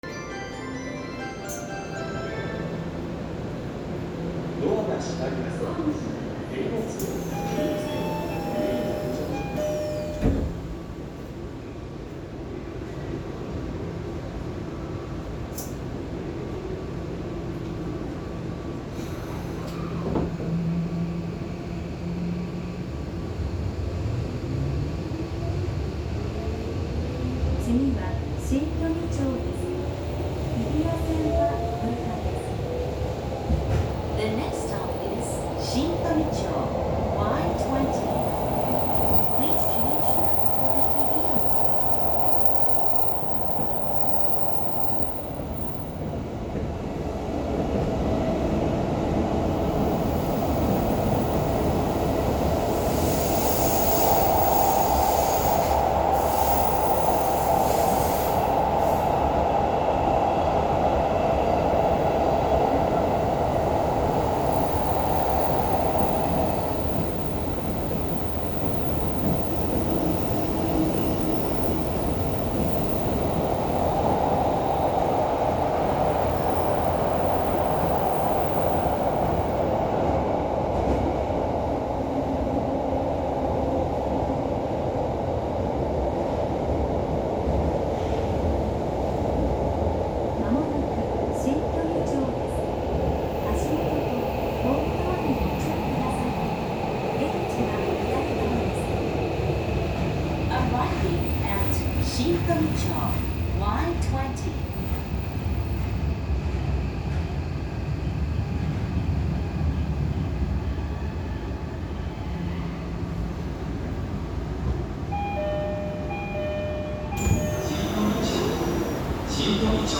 ・17000系三菱PMSM走行音
10両編成は、近年の新車/機器更新車にありがちな三菱のPMSMを採用しており、起動音はほとんどありません。音鉄趣味的にはつまらない車両かもしれませんが、静粛性はその分非常に優れています。